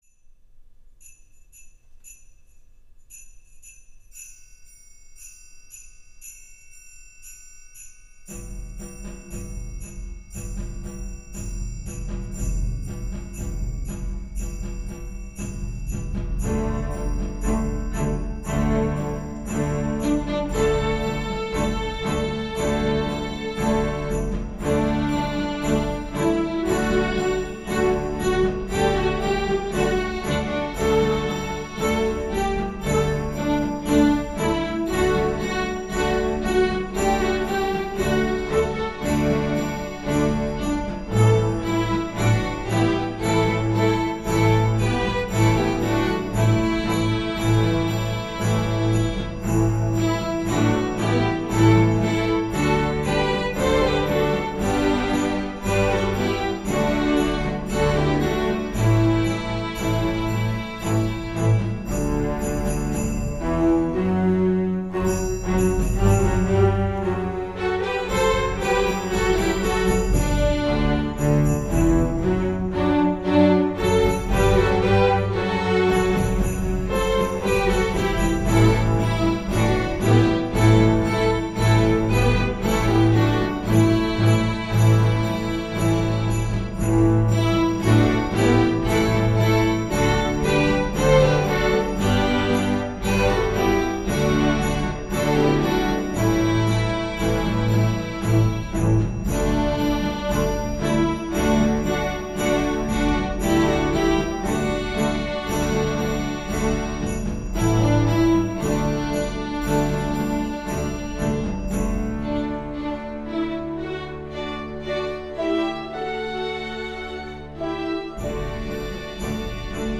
Category: String Orchestra
Grade: Medium Easy
Instrumentation: str=8.8(3).5.5.5,4 perc,pf